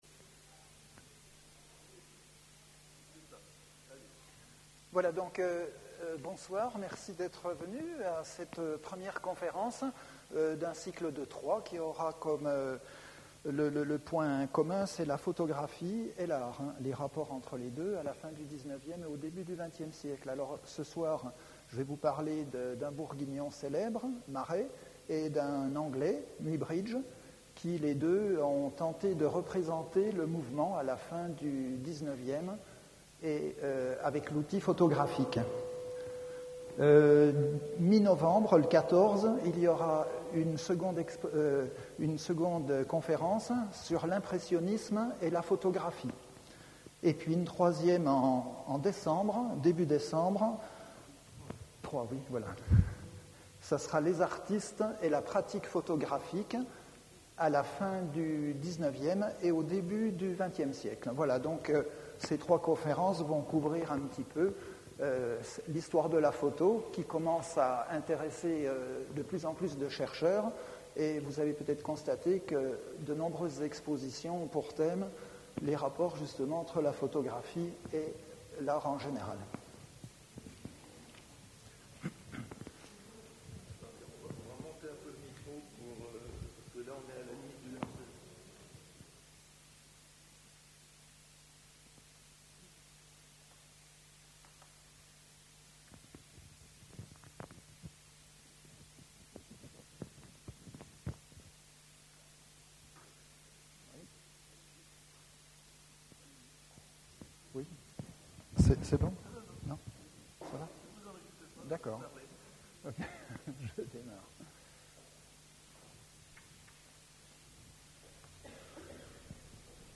L'image ou la troisième étape de l'évolution de l'humanité Conférences | Université pour Tous de Bourgogne